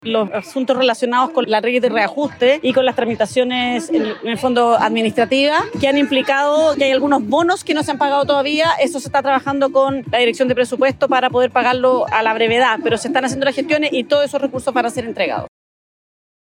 En ese sentido, la subsecretaria de Educación, Alejandra Arratia, explicó que este recorte se debe trámites pendientes en la Ley de Reajustes, asegurando que una vez se concluyan, se efectuará el pago de la deuda pendiente.